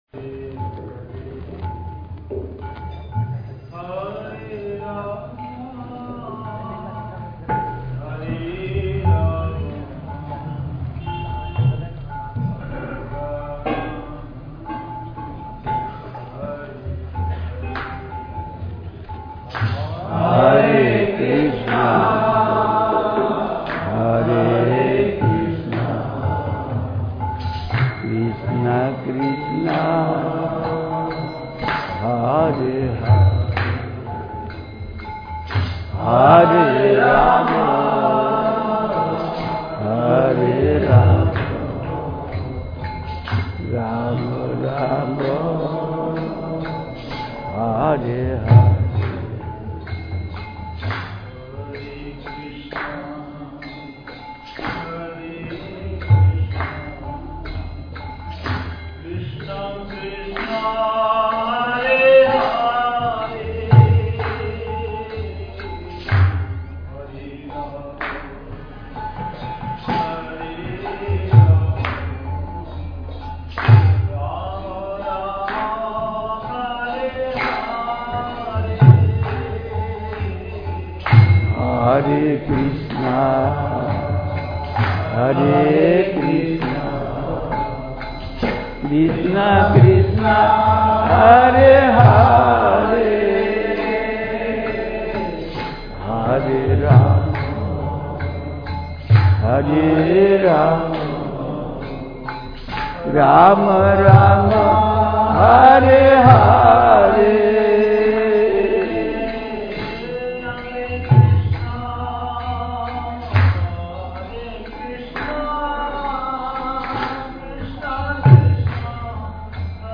Live Kirtans from Sree Chaitanya Gaudiya Math